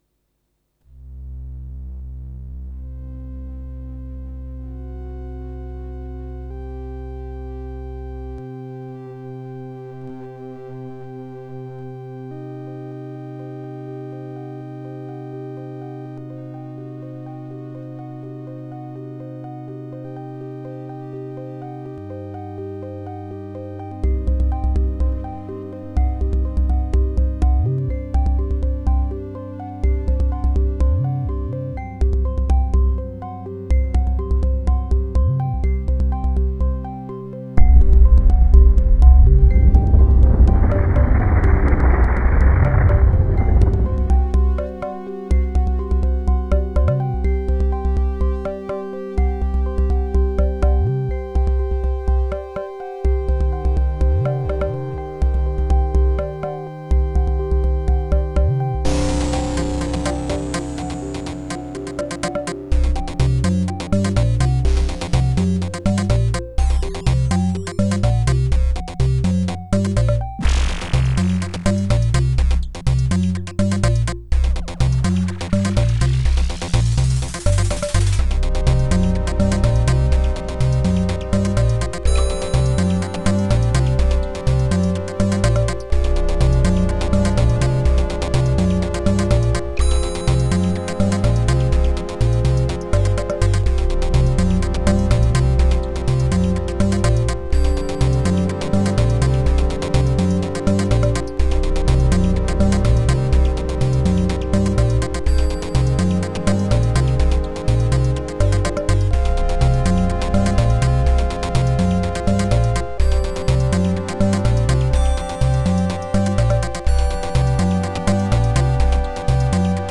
486DX-33, Sound Blaster
44 kHz stereo FLAC recording
(Speaker out on left, Line Out on right)